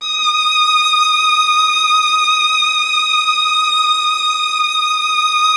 MELLOTRON.18.wav